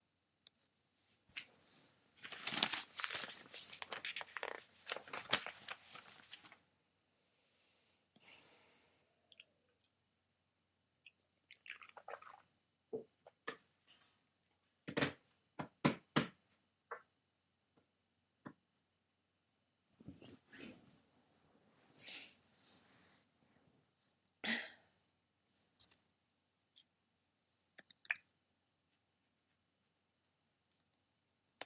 Field Recording Number Seven!
Sounds Heard: paper shuffling, knocking, breathing, footsteps